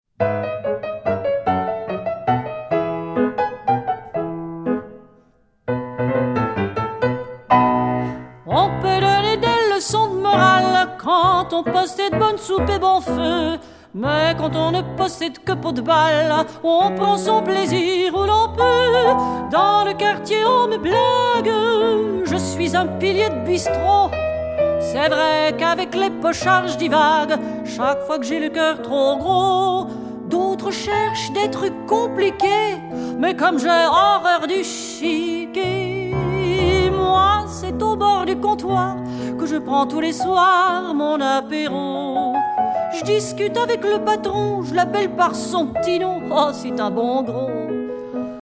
chant
piano
elle passe d'un climat musical à l'autre avec souplesse et élégance.